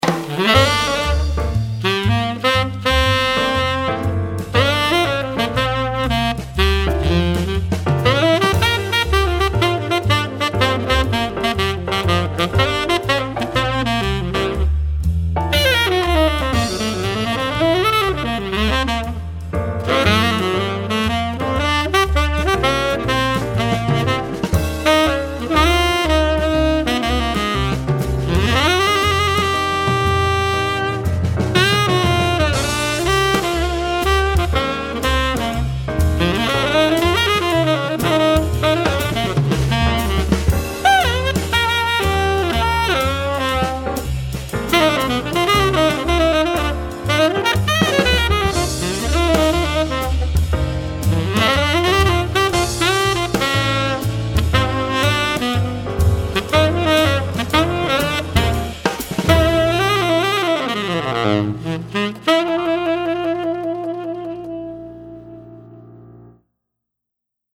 The mouthpiece features a deep, dark, soft sound, and clear tone for sub-tone.
Type: Tenor
Dark,
Fat sound,
Has core sound in sub-tone
StyleJazz
MasterTenorJazz.mp3